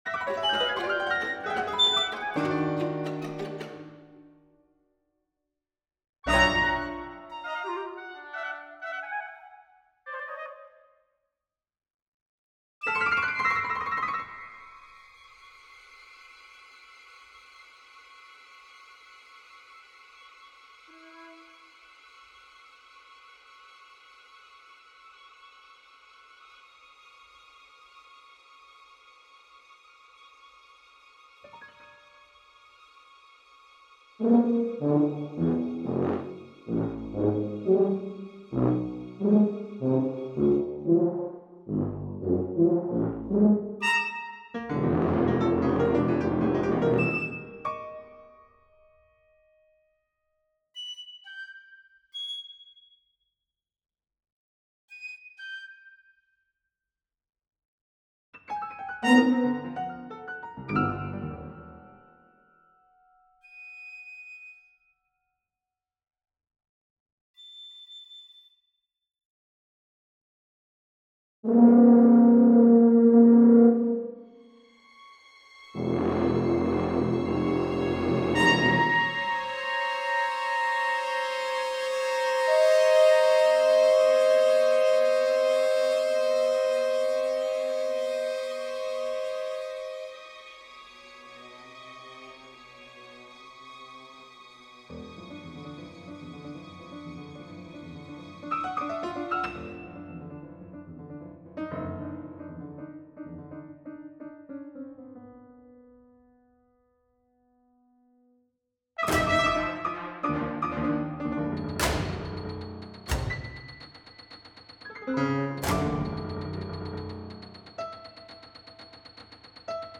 2001_01.Pois for mechanical piano ...